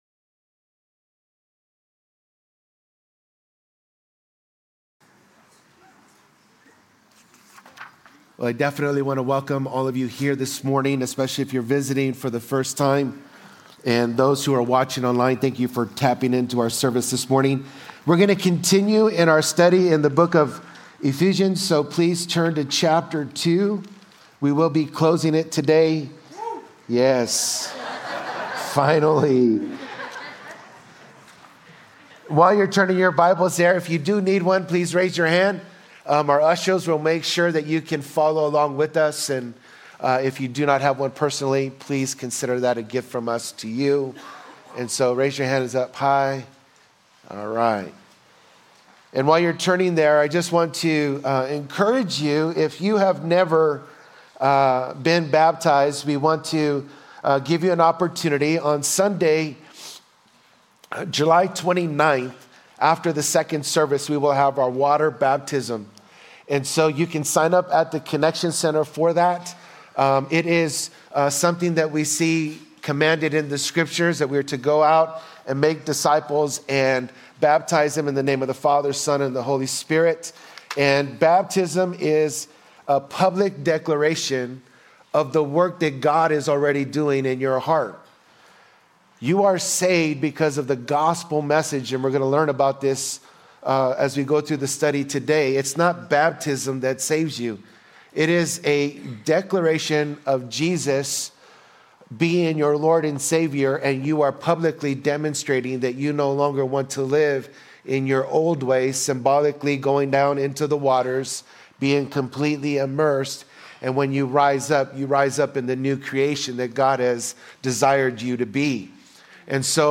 Calvary Chapel Saint George - Sermon Archive
Related Services: Sunday Mornings